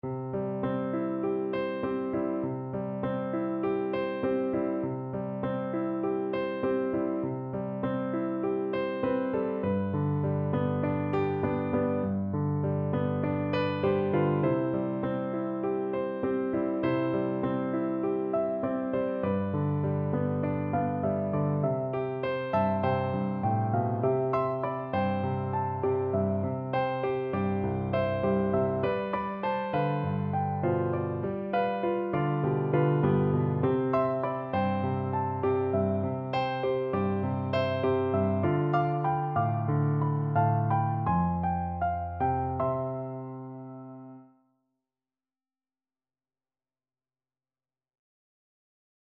Play (or use space bar on your keyboard) Pause Music Playalong - Piano Accompaniment Playalong Band Accompaniment not yet available transpose reset tempo print settings full screen
Gently Flowing = c.100
C major (Sounding Pitch) (View more C major Music for Cello )
4/4 (View more 4/4 Music)